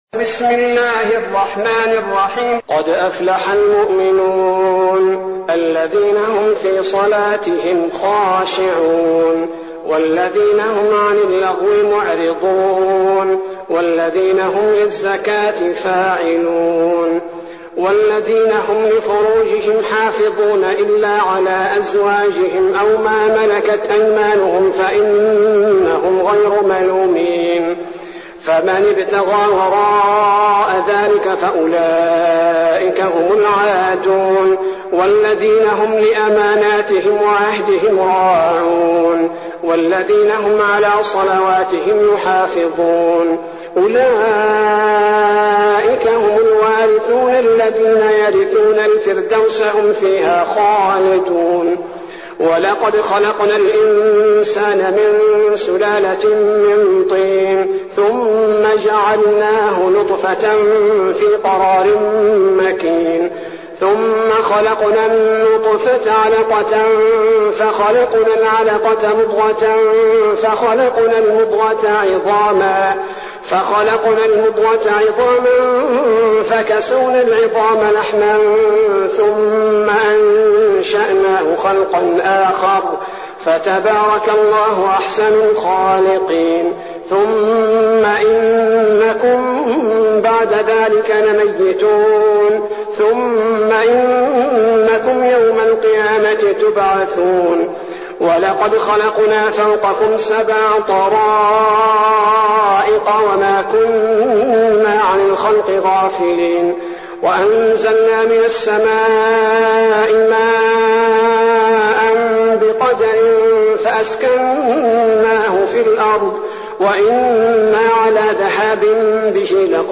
المكان: المسجد النبوي الشيخ: فضيلة الشيخ عبدالباري الثبيتي فضيلة الشيخ عبدالباري الثبيتي المؤمنون